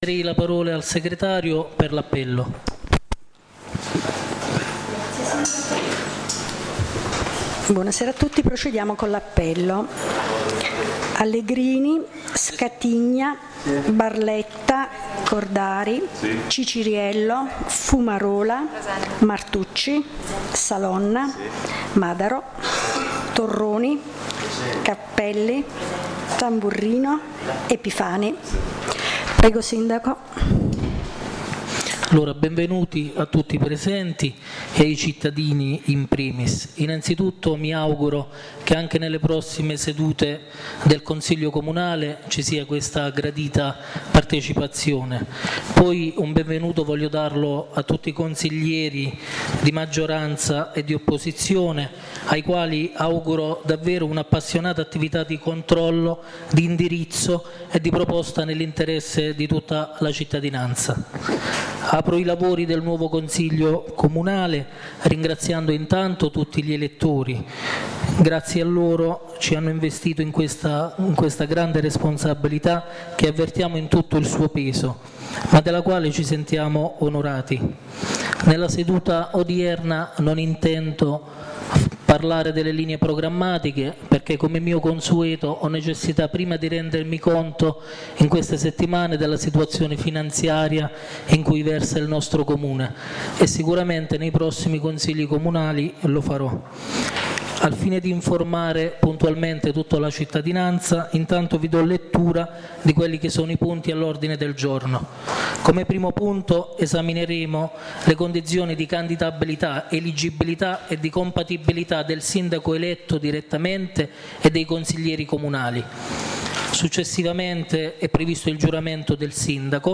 La registrazione audio e le foto del Consiglio Comunale di San Michele Salentino del 29/06/2017 (il primo dell’amministrazione Allegrini).